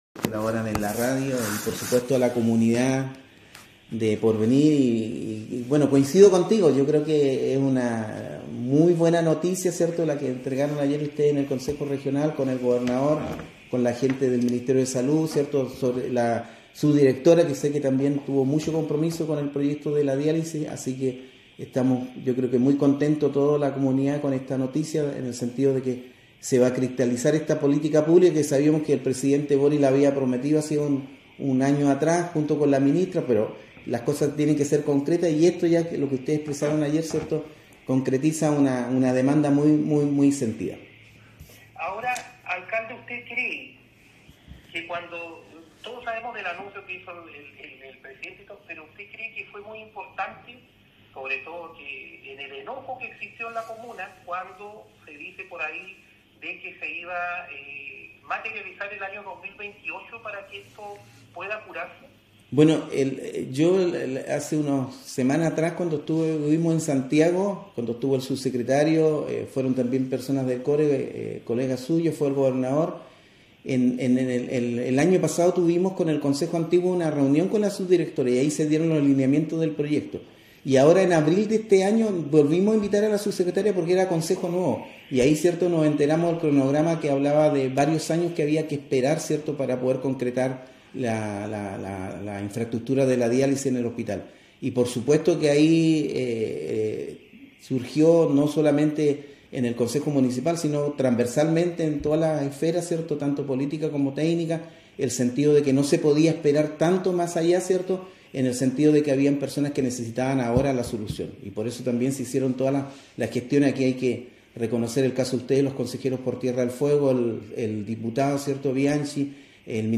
Durante la entrevista realizada en un espacio radial local, el alcalde de Porvenir José Gabriel Parada Aguilar, destacó la importancia de este avance, recordando que la promesa presidencial se remonta al año anterior.